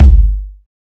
Kicks
KICK.121.NEPT.wav